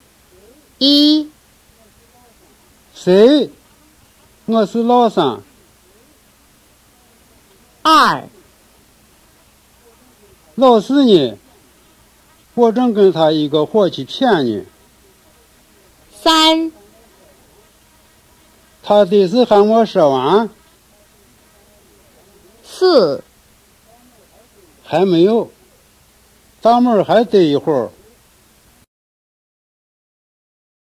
In each of the audio files below, the speaker will say the following, at least how they would say the same thing in their dialect.
2. Xī’ān Dialect (Central Mandarin Sub-group; Shaanxi Province)
02-xian-hua.m4a